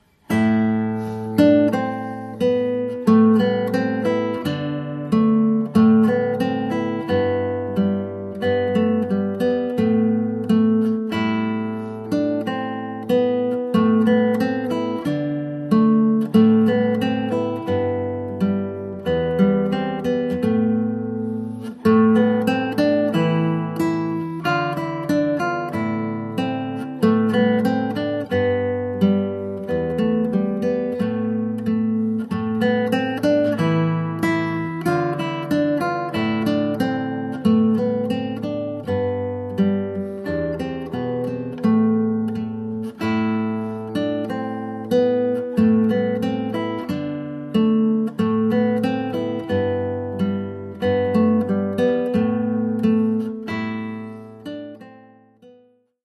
Oeuvre pour guitare solo.